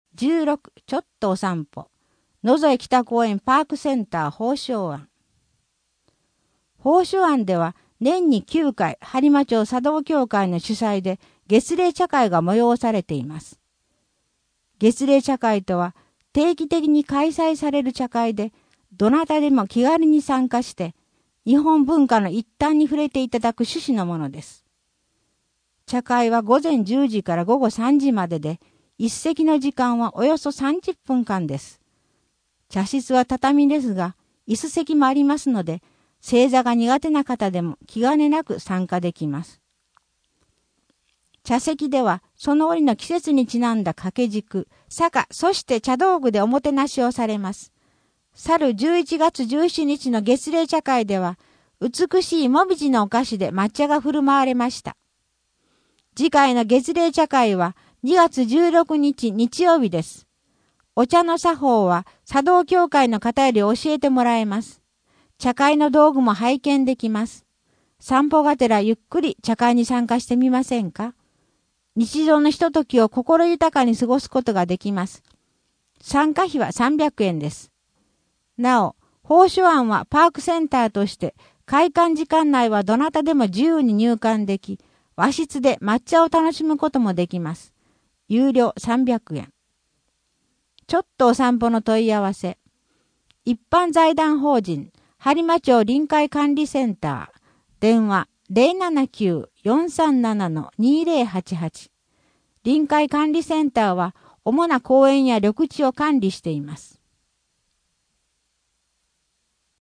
声の「広報はりま」2月号
声の「広報はりま」はボランティアグループ「のぎく」のご協力により作成されています。